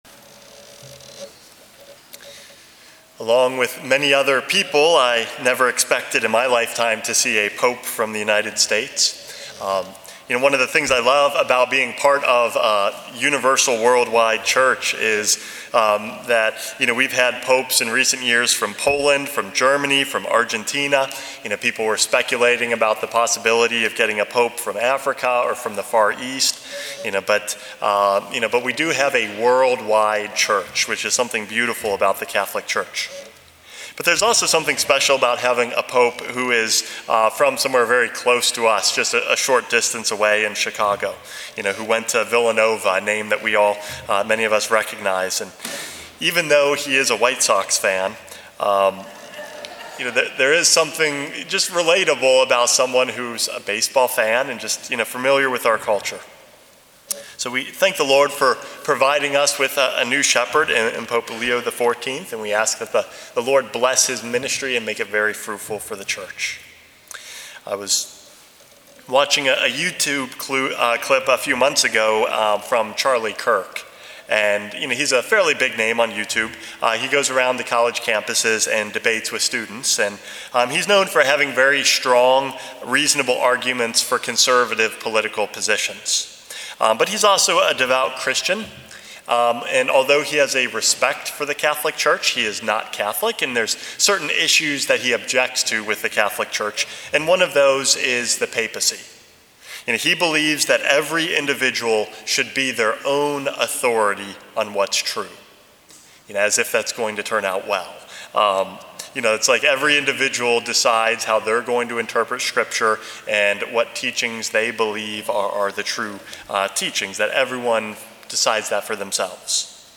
Homily #449 - The Gift of a Shepherd